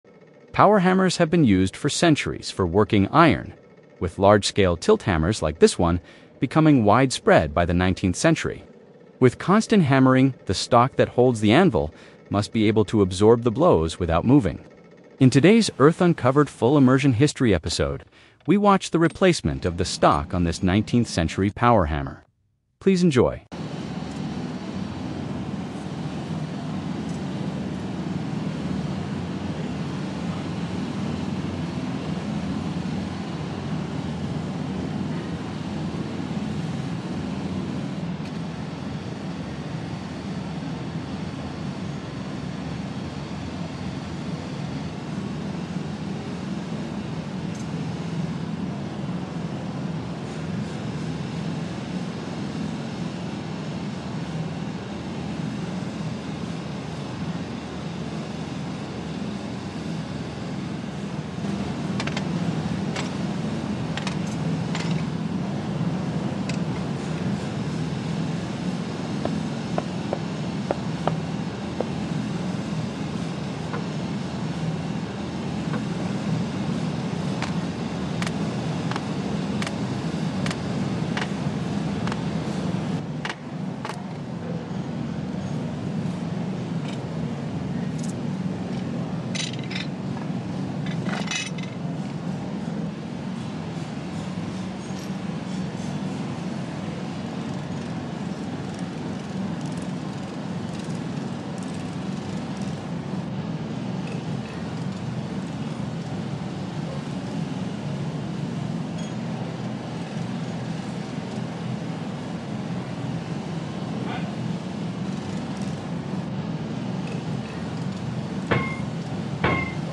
That power hammer knocks like a woodpecker.
Steady hammer swing too.